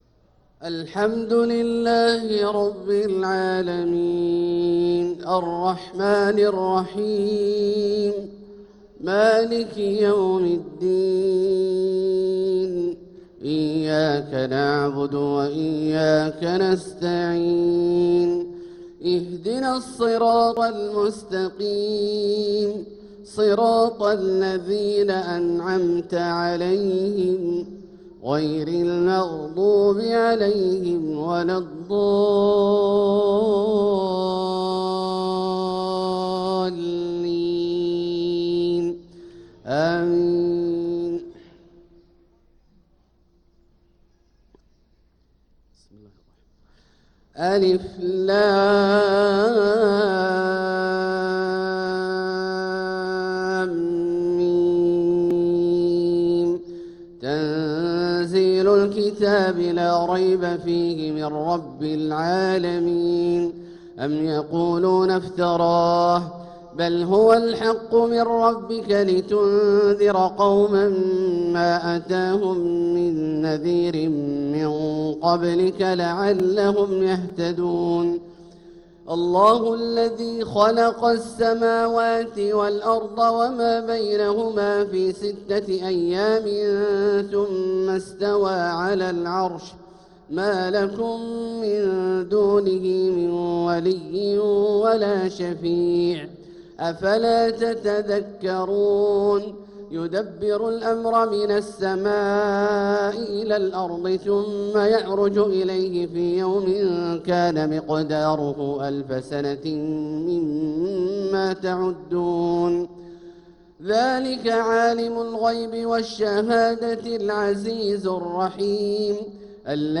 صلاة الفجر للقارئ عبدالله الجهني 12 صفر 1446 هـ
تِلَاوَات الْحَرَمَيْن .